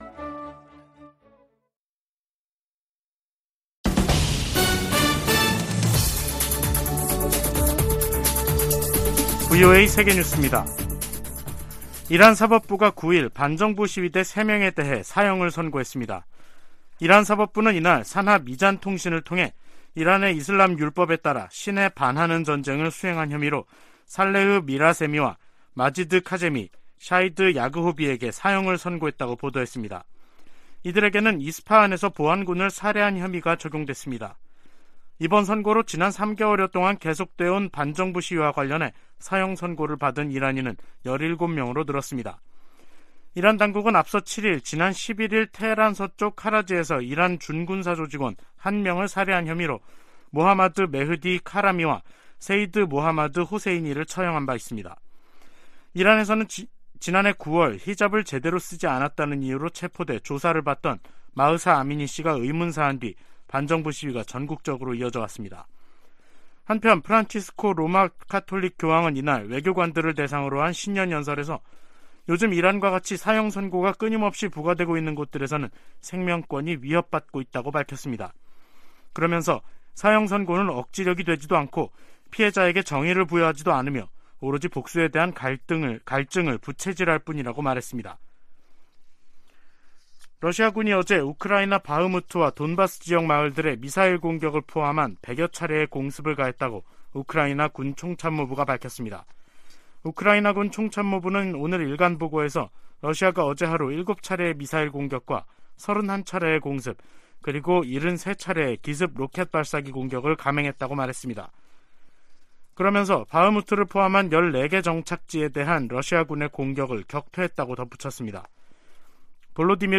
VOA 한국어 간판 뉴스 프로그램 '뉴스 투데이', 2023년 1월 9일 3부 방송입니다. 미 국무부는 6년째 공석인 북한인권특사 임명 여부와 관계 없이 미국은 북한 인권 문제에 집중하고 있다고 밝혔습니다. 미국의 한반도 전문가들은 실효성 논란에 휩싸인 9.19 남북 군사합의와 관련해, 이를 폐기하기보다 북한의 도발에 대응한 ‘비례적 운용’이 더 효과적이라고 제안했습니다.